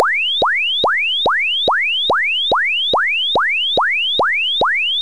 SWEEP.WAV